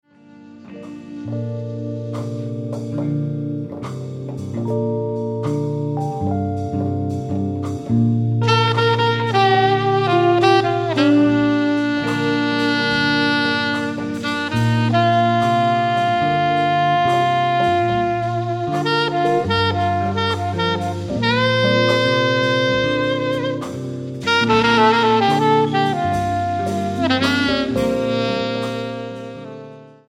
sax, bass, vocals
percussion
keyboard
trombone
saxes